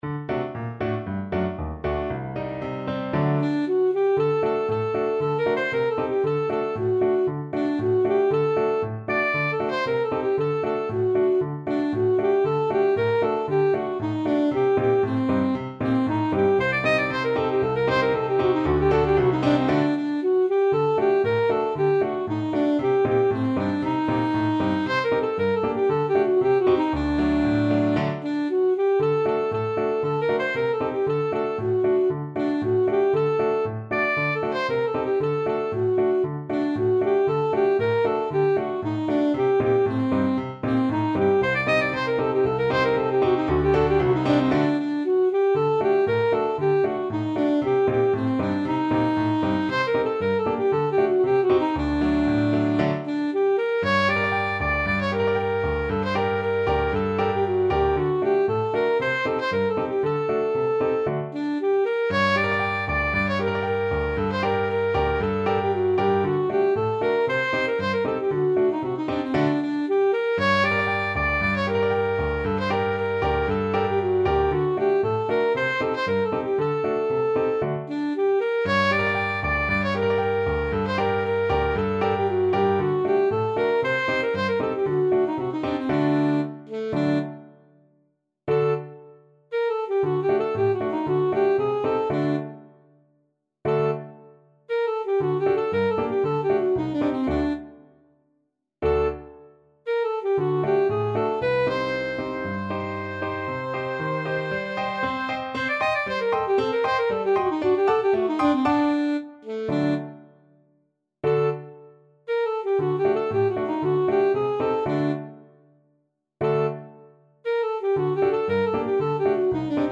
Alto Saxophone
2/4 (View more 2/4 Music)
Allegro =c.116 (View more music marked Allegro)
Traditional (View more Traditional Saxophone Music)